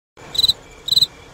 Play Cri Cri Grillo - SoundBoardGuy
PLAY Grillo Cri Cri F
grillo-cri-cri-cri-audiotrimmer.mp3